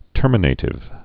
(tûrmə-nātĭv)